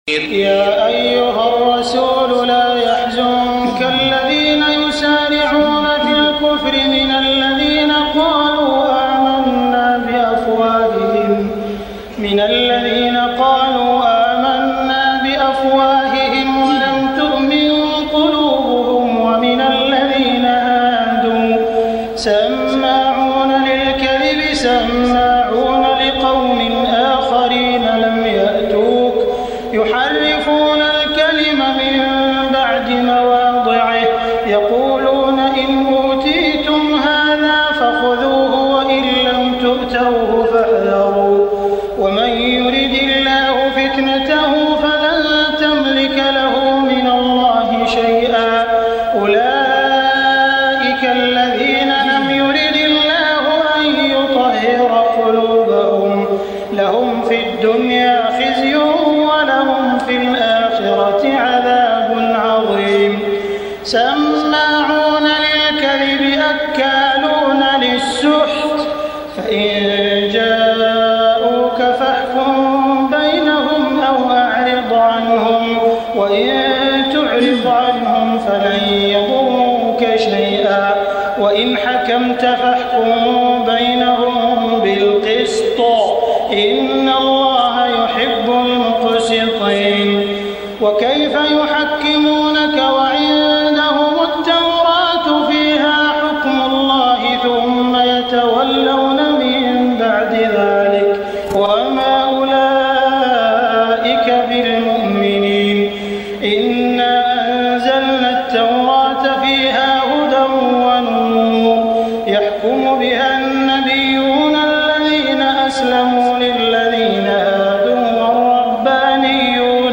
تهجد ليلة 26 رمضان 1423هـ من سورة المائدة (41-81) Tahajjud 26 st night Ramadan 1423H from Surah AlMa'idah > تراويح الحرم المكي عام 1423 🕋 > التراويح - تلاوات الحرمين